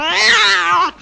PixelPerfectionCE/assets/minecraft/sounds/mob/cat/hitt3.ogg at mc116